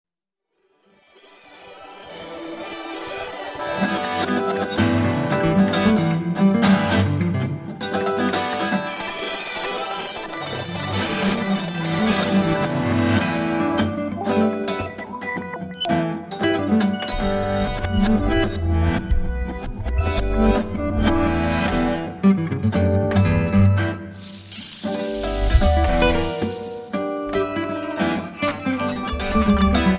Acoustic, Electric Guitar and SuperCollider
Acoustic, Electric Guitar and Percussion